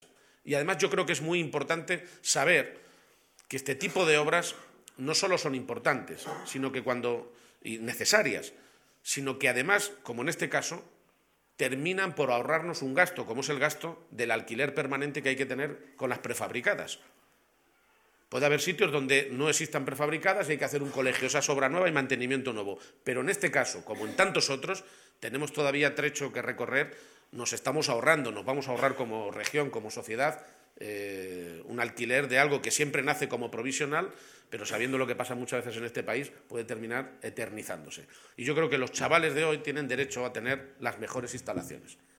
corte_presidente_aulas.mp3